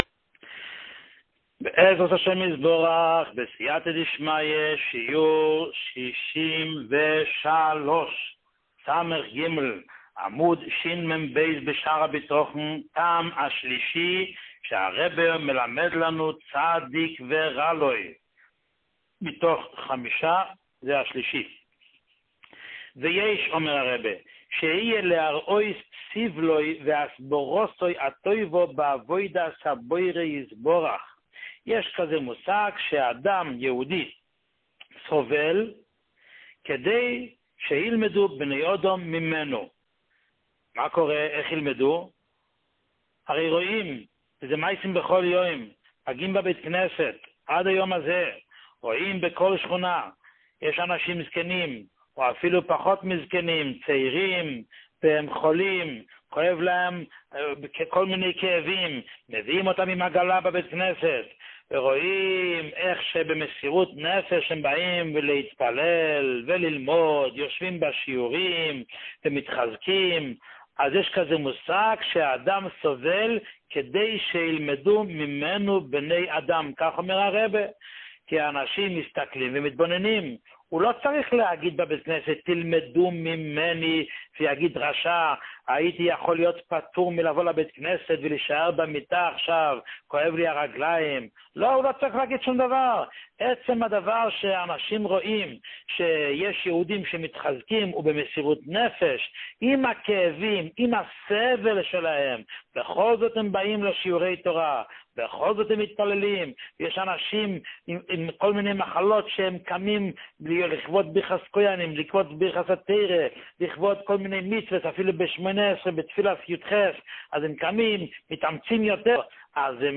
שיעור 63